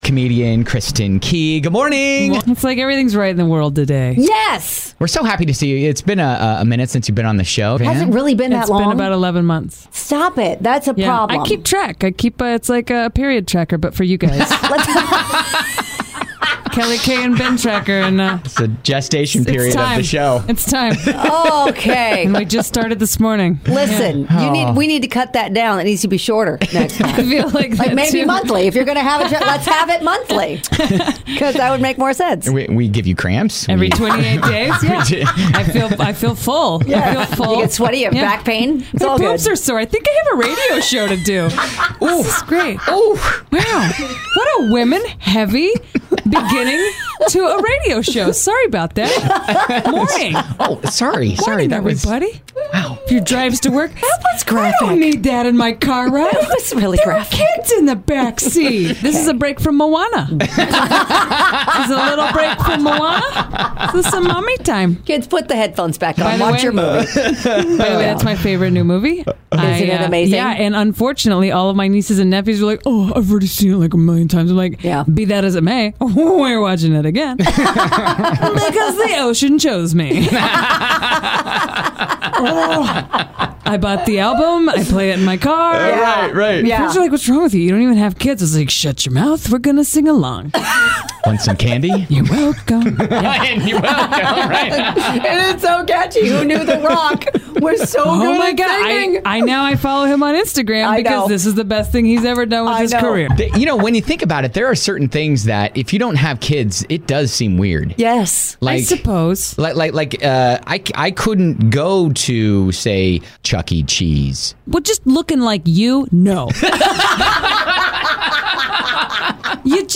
One of our faves stopped by the studio...she knows exactly how long it's been!